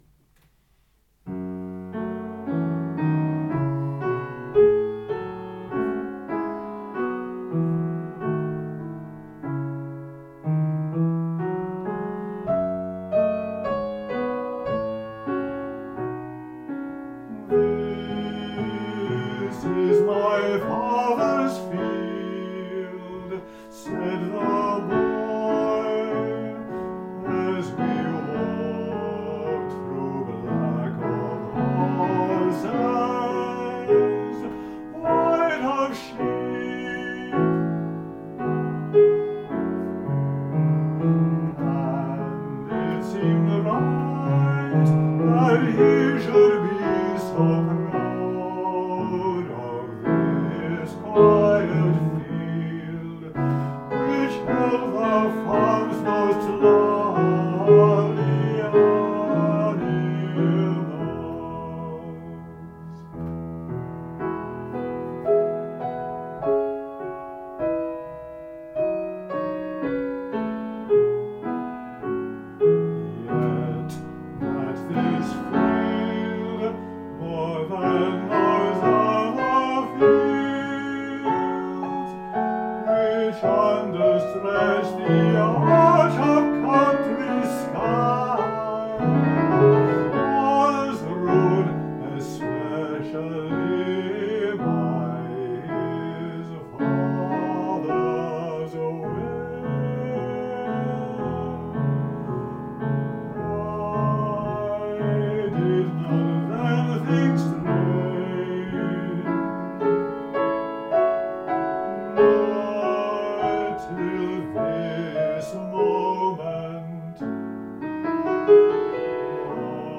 piano
baritone